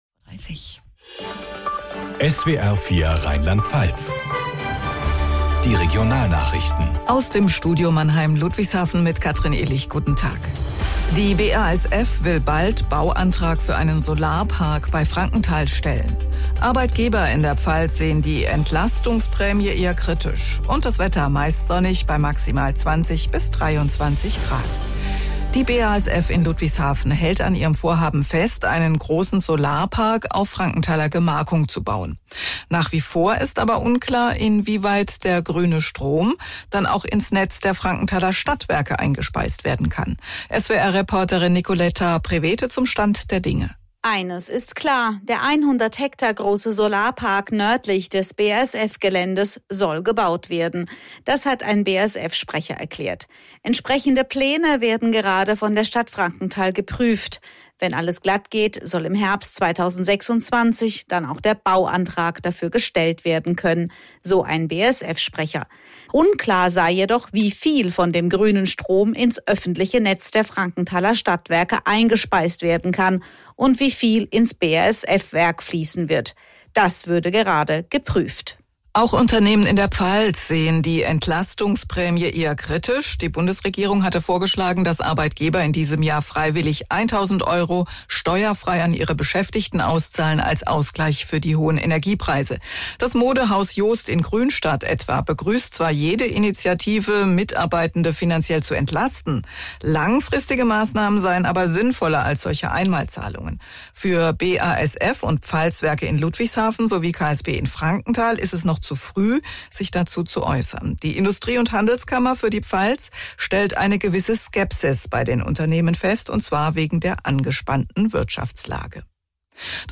SWR Regionalnachrichten aus Ludwigshafen
radionachrichten.mp3